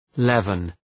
Shkrimi fonetik {‘levən}